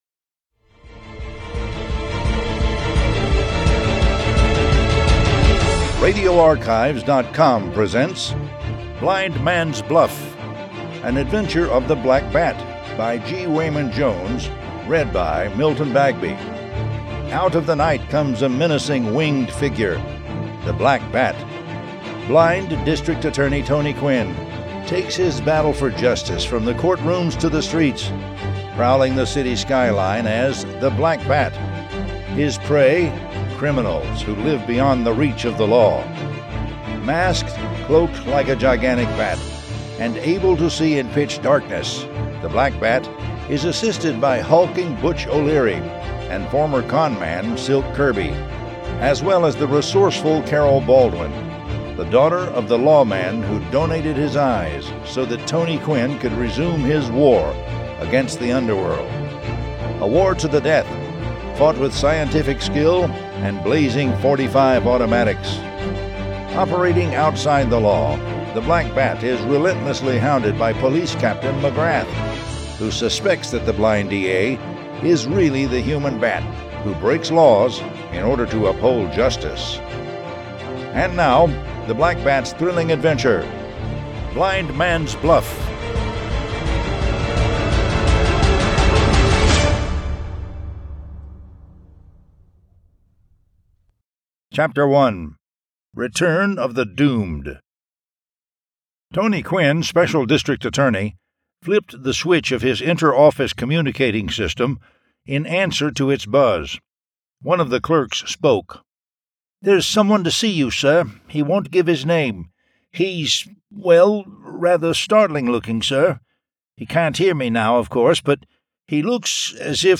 The Black Bat Audiobook #35 Blind Man's Bluff